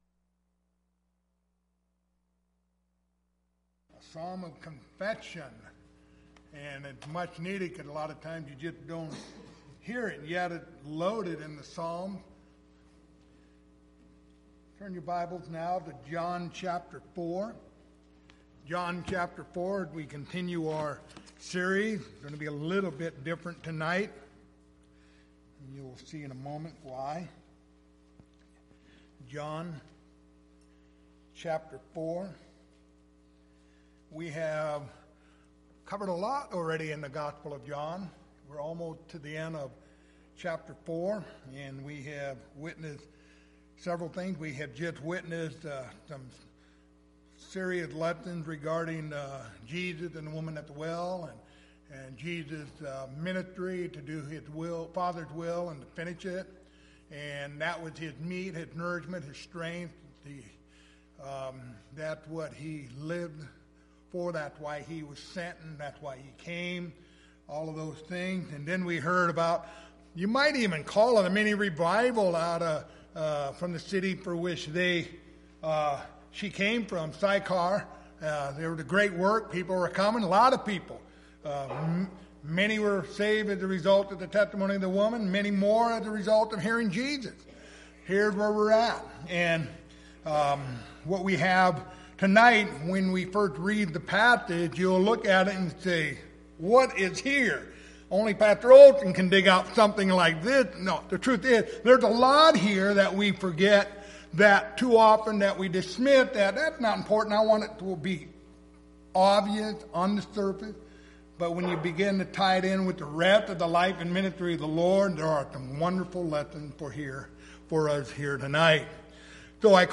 Passage: John 4:43-45 Service Type: Wednesday Evening